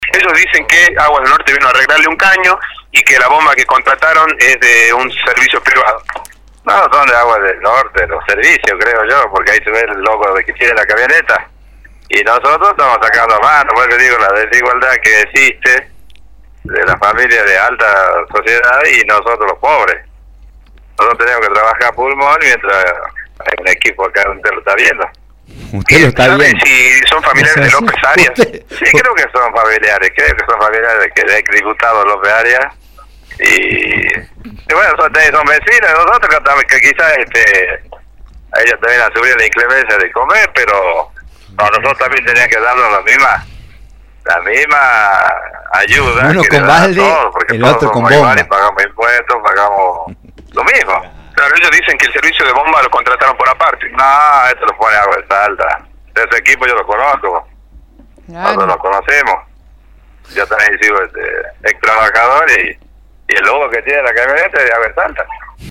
Los vecinos manifestaron su indignación ante esta desigualdad a nuestro móvil que se encontraba en el lugar.
VECINO-DENUNCIA-TRATO-PRIVILEGIADO.mp3